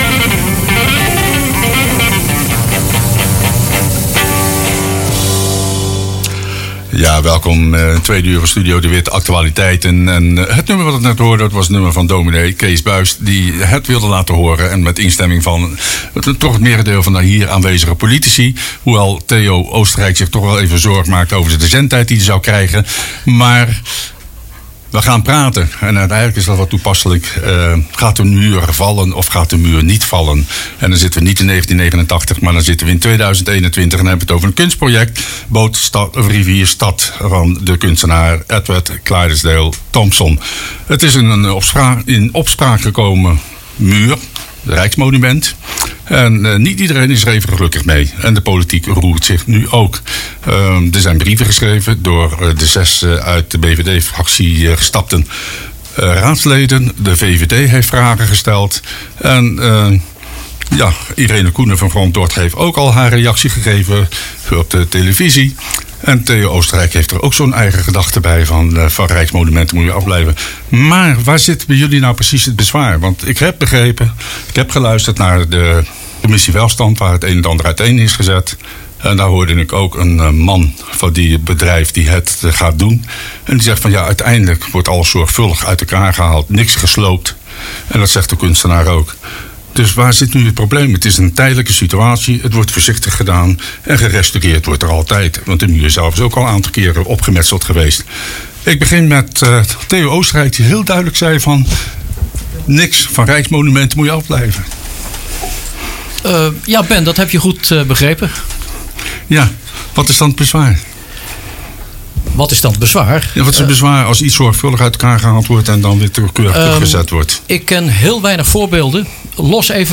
Kitty bij Studio de Witt
Luister hieronder naar de volledige uitzending van Studio De Witt waarin fractievoorzitter Kitty Kruger uitlegt waarom wij tegen dit project zijn.